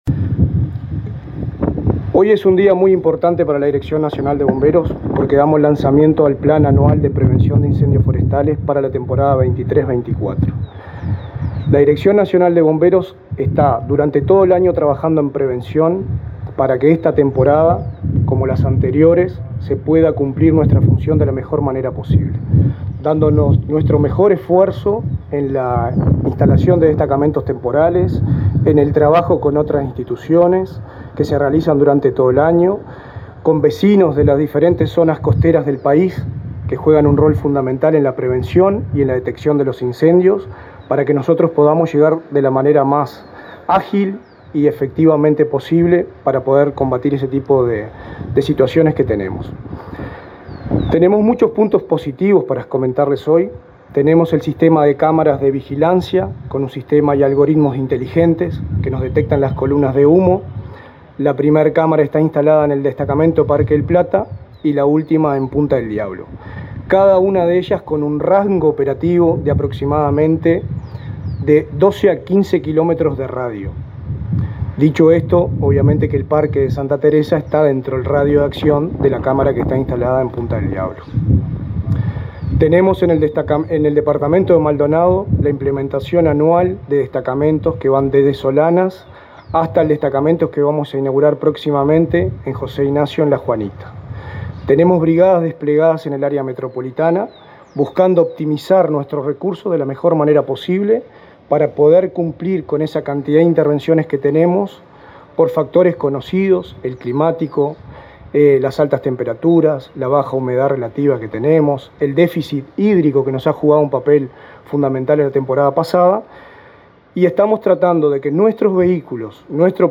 Palabra de autoridades en lanzamiento de plan de protección frente a incendios forestales
Palabra de autoridades en lanzamiento de plan de protección frente a incendios forestales 29/11/2023 Compartir Facebook X Copiar enlace WhatsApp LinkedIn El subdirector nacional de Bomberos, Richard Barboza; el director nacional de Emergencias, Santiago Caramés; y el ministro del Interior, Nicolás Martinelli, participaron este miércoles 29 en Rocha, del lanzamiento del Plan Nacional de Protección Integral frente a Incendios Forestales 2023-2024.